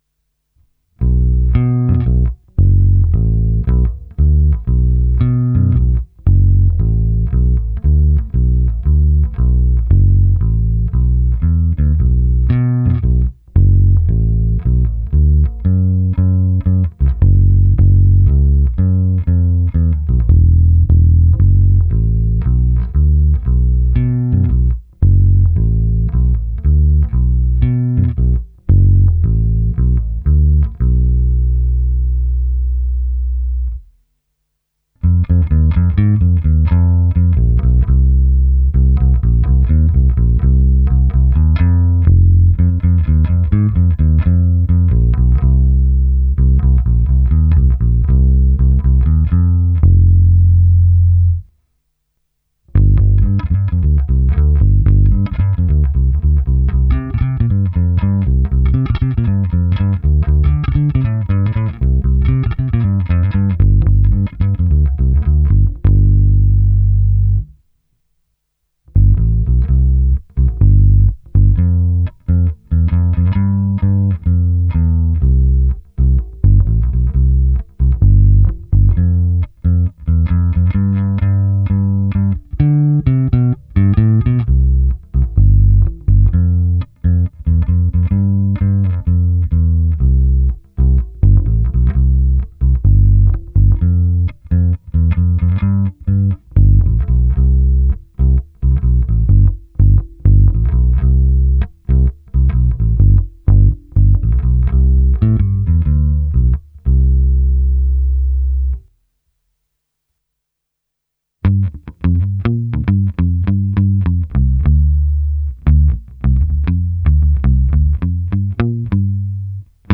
Ukázka přes Darkglass Harmonic Booster, TC Electronic SpetraComp a Darkglass Microtubes X Ultra se zapnutou simulací aparátu. V ukázce je použita i hra trsátkem, jak se zatlumením, tak bez, zkreslení a dokonce i slap, přestože slap s těmito strunami je to takový trochu zvláštní, jiný, nevšední, ale nevím, jak moc v reálu použitelný.